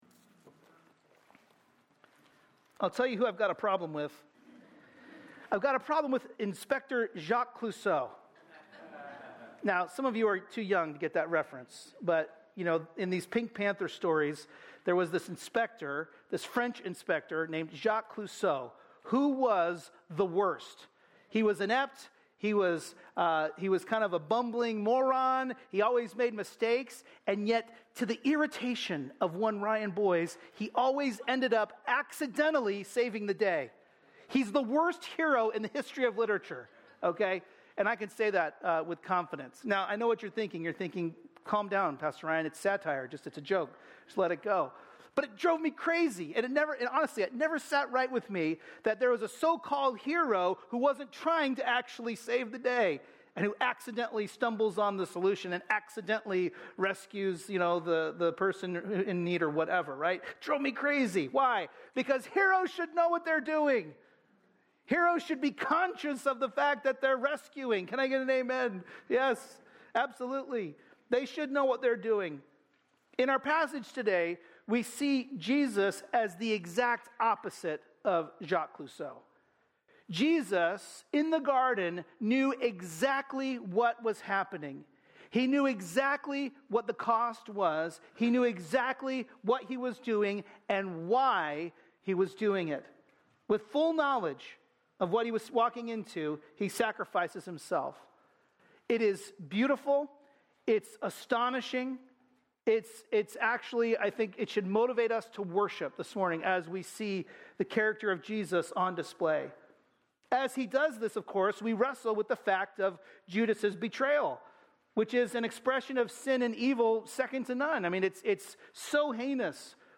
sermon-4_27-MP3.mp3